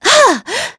Veronica-Vox_Attack3.wav